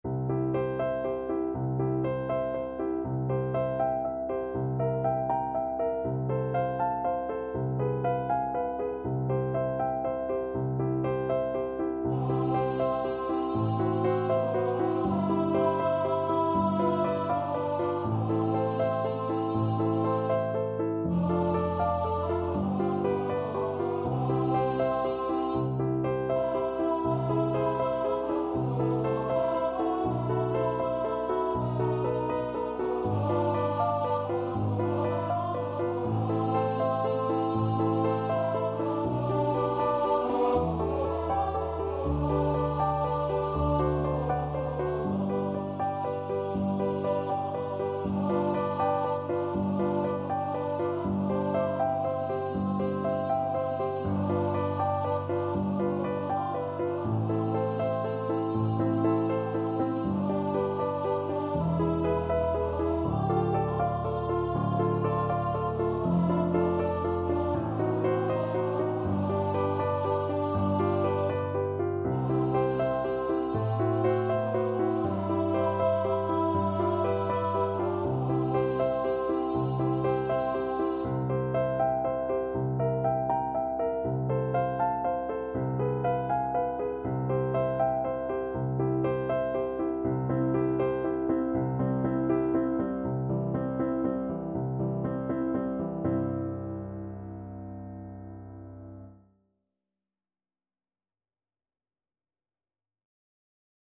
Andante
Classical (View more Classical Tenor Voice Music)